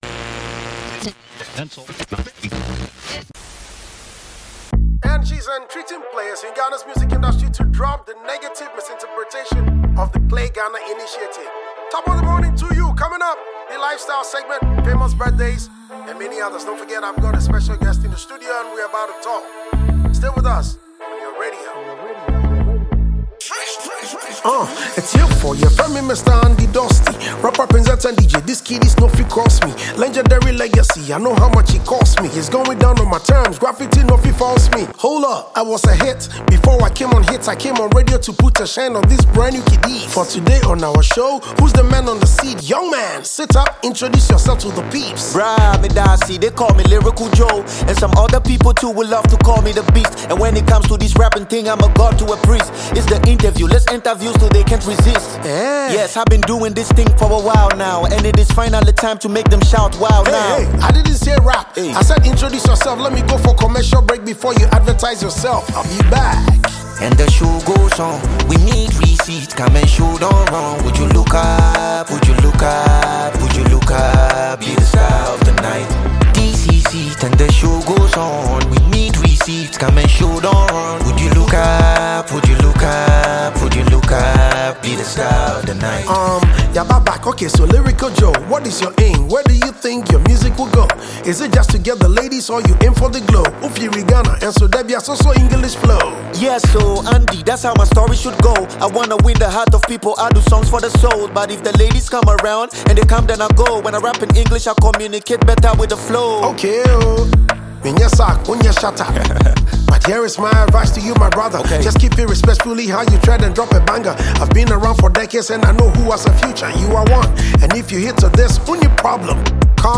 tongue-twisting